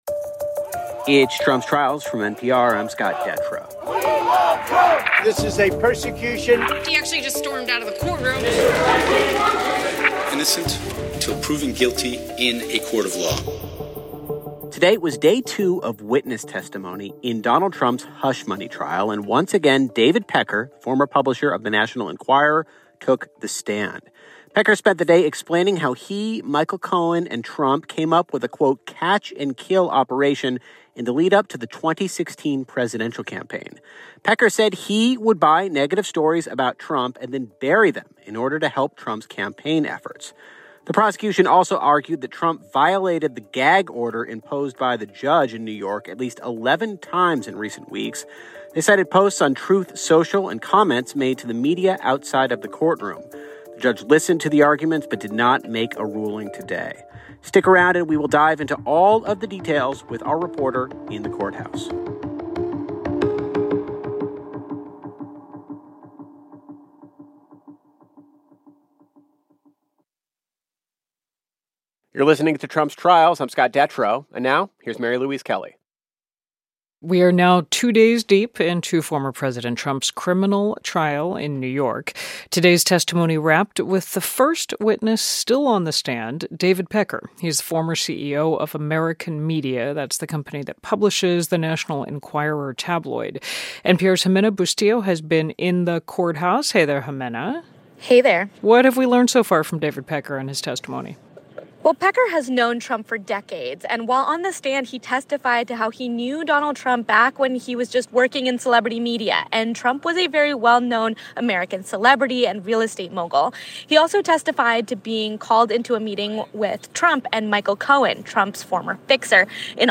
WBUR WBUR 90.0 WBUR - Boston's NPR News Station Listen Live : Loading...